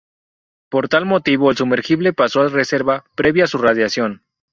su‧mer‧gi‧ble
/sumeɾˈxible/